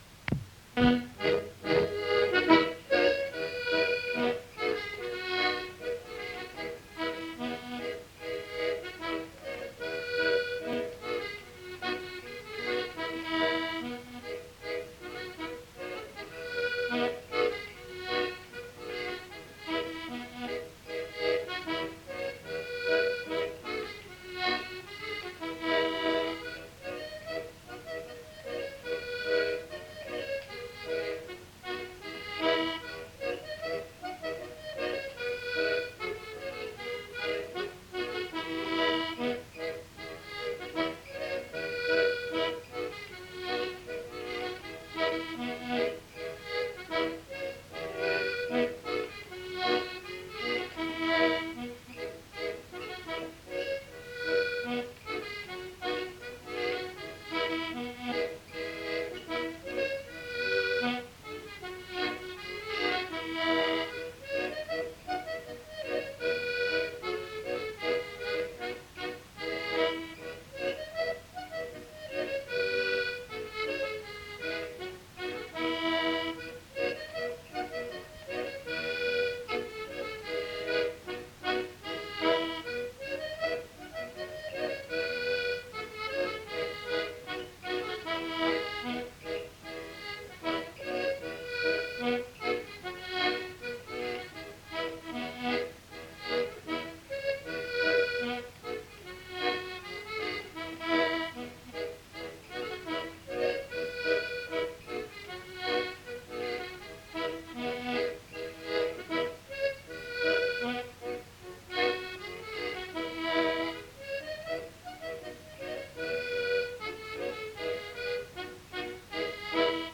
Aire culturelle : Cabardès
Lieu : Villardonnel
Genre : morceau instrumental
Instrument de musique : accordéon diatonique
Danse : scottish